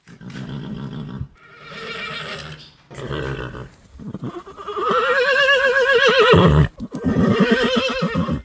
Das Brummeln und Wiehren von Mr.P. als Klingelton ;-)
Es beginnt dezent und endet eher dramatisch 😉